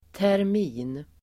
Uttal: [tärm'i:n]